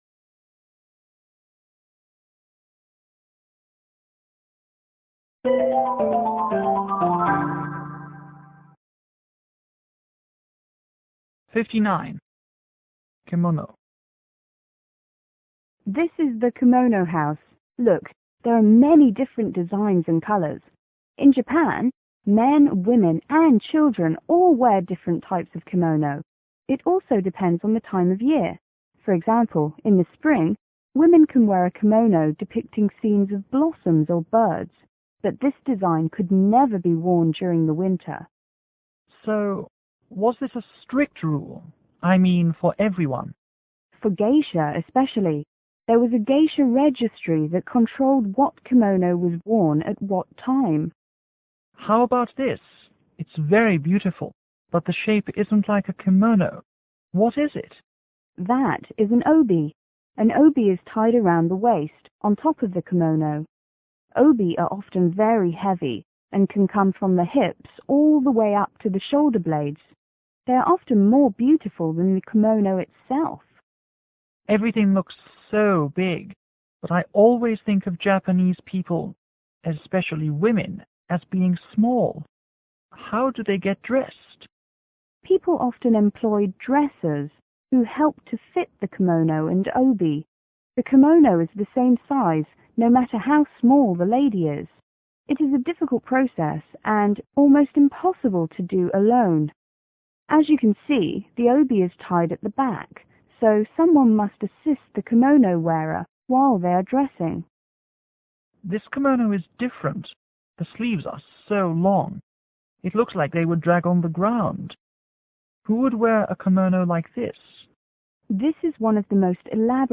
G:Museum guide  T：Tourist